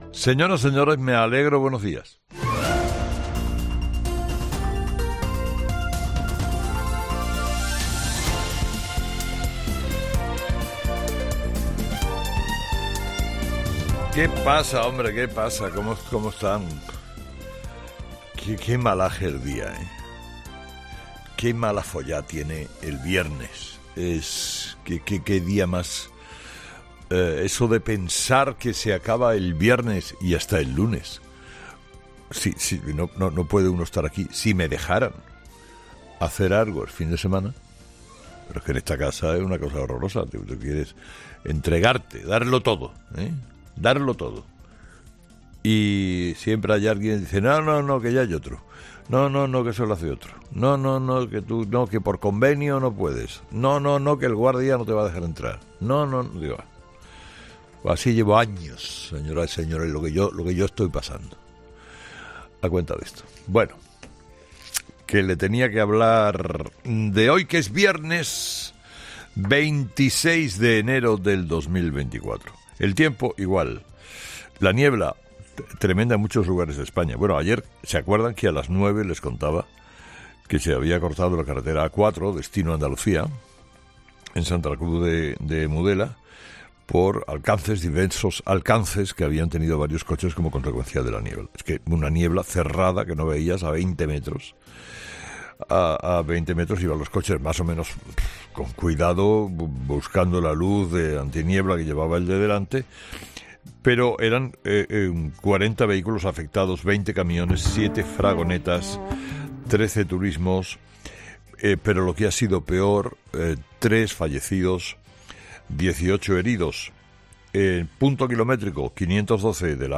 Carlos Herrera, director y presentador de 'Herrera en COPE', comienza el programa de este viernes analizando las principales claves de la jornada que pasan, entre otras cosas, por el auto del juez García Castellón y los tipos de terrorismo.